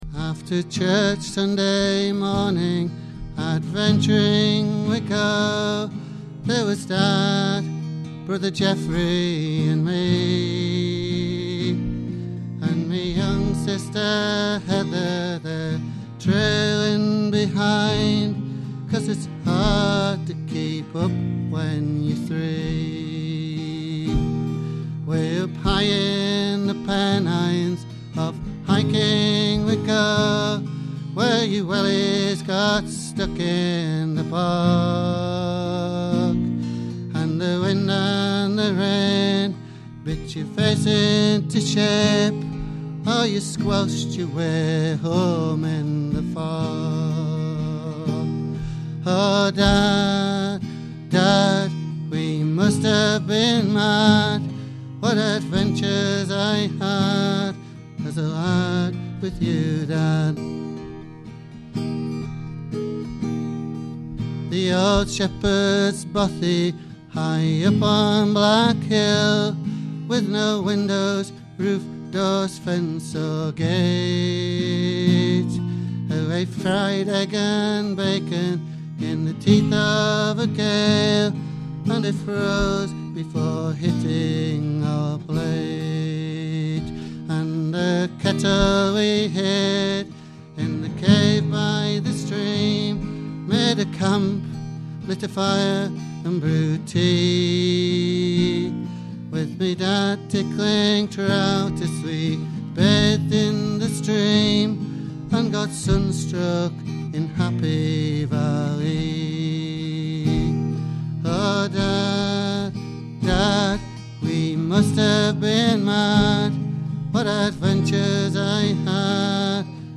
Influences 60s and 70s rock folk, country and pop.